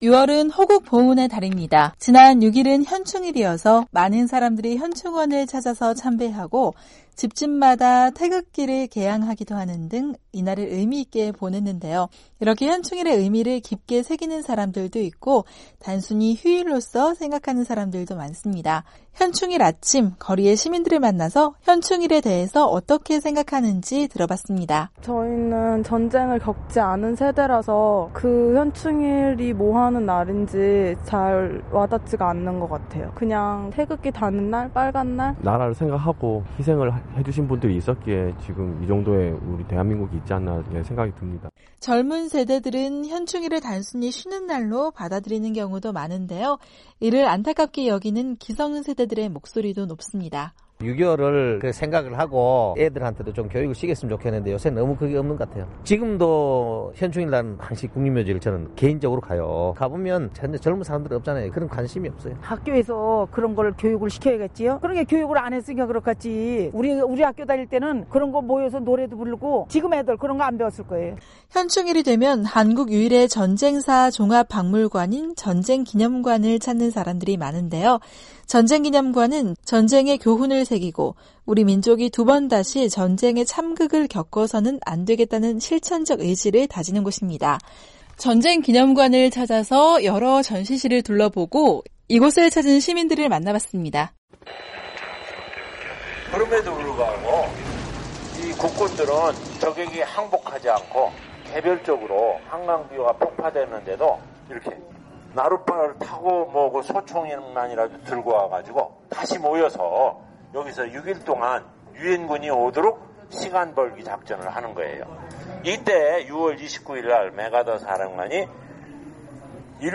현충일을 맞아 전쟁기념관을 찾은 시민들을 만나봤습니다.